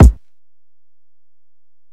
Kick (16).wav